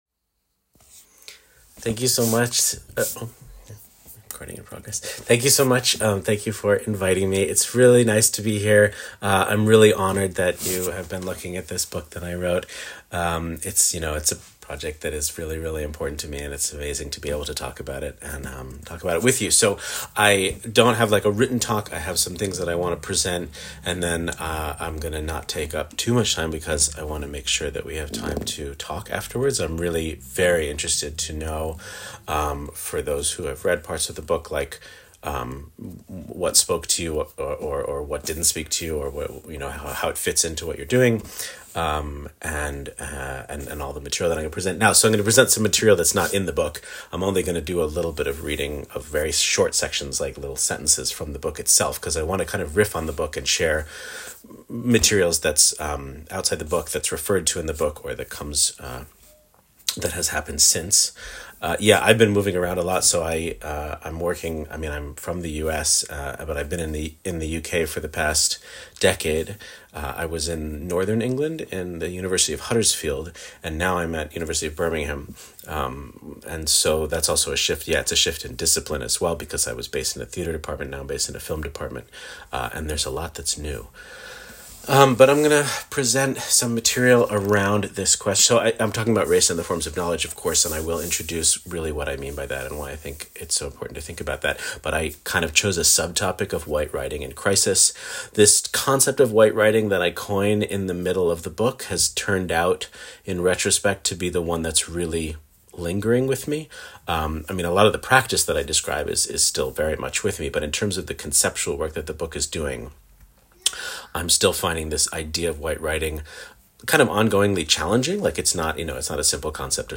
22 October 2025 Department of Dance Ohio State University